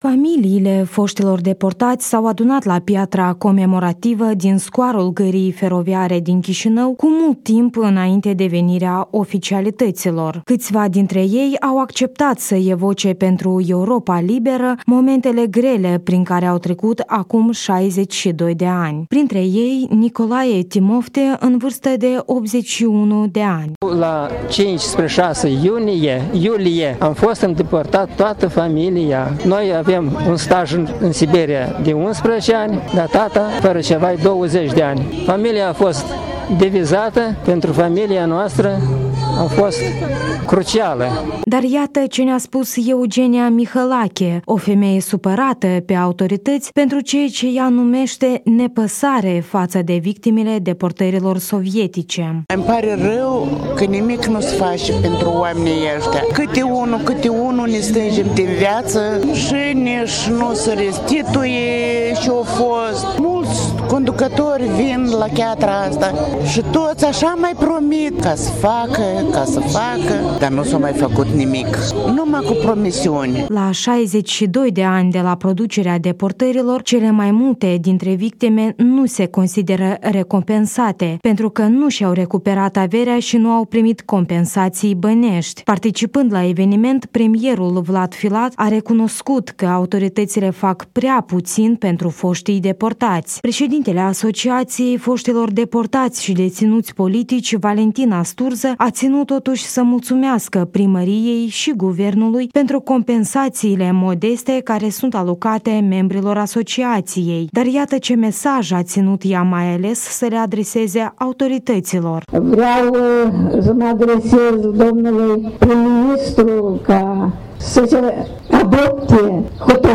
Victimele celui de al doilea val de deportări staliniste, comemorate la Chişinău
Familiile foştilor deportaţi s-au adunat la piatra comemorativă din scuarul gării feroviare din Chişinău cu mult timp înainte de venirea oficialităţilor.